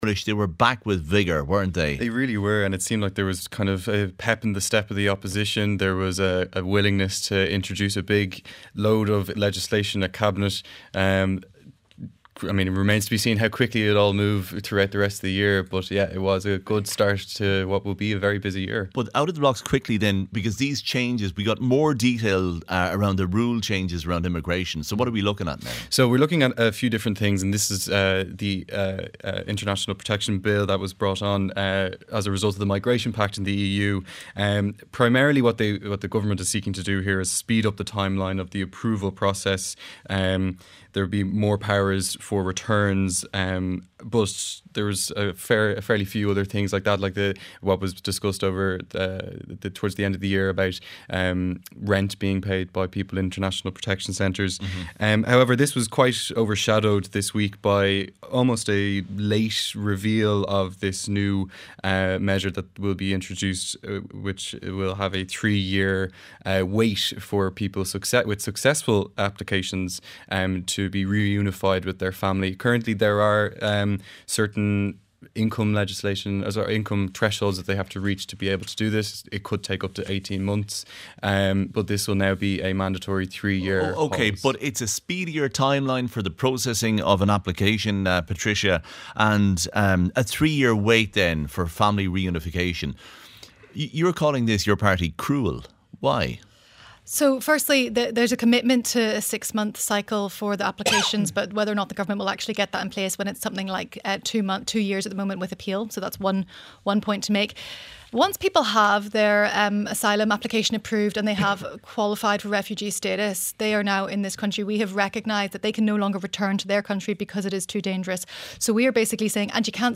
Our panel of Matt Carthy, Sinn Féin TD for Cavan Monaghan, Patricia Stephenson, Social Democrats Senator, Séamus McGrath, Fianna Fáil TD for Cork South-Central and spokesperson on Housing.